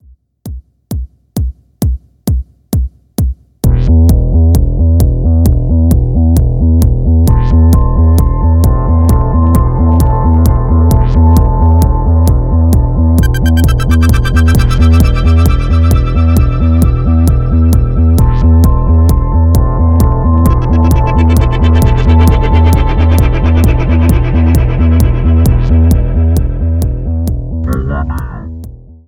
[music] new techno track clip
Here is my first foray into producing techno music with my own vocal (lol) and samples from electronic music free tracks, 30 seconds. It’s laughably simple, but I have never made a song before, so hopefully I will produce newer and better songs in the future, if we even have a future anymore.
first-techno-track-millenium.mp3